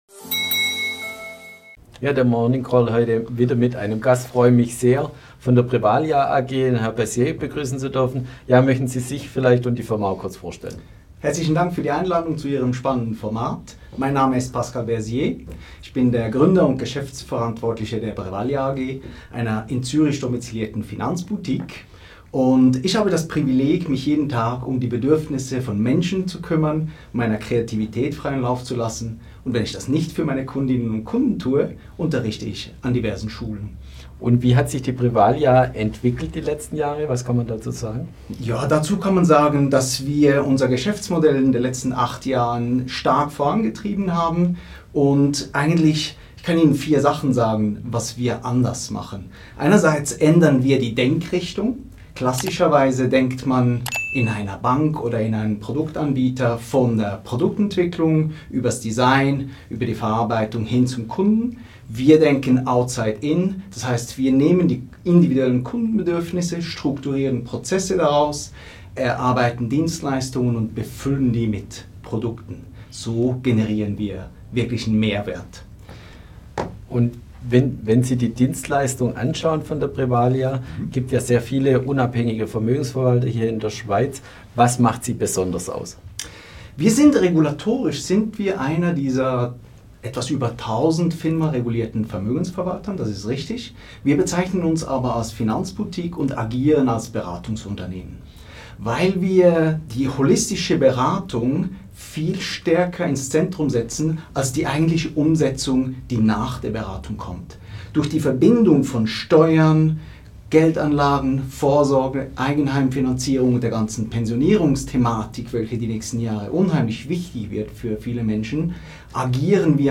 Heute im BX Morningcall zu Gast